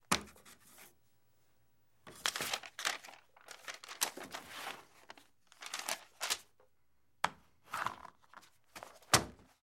Pencil Writing On Paper Movement; Pencil Writing On Paper With Pad Down At End. - Pencil Writing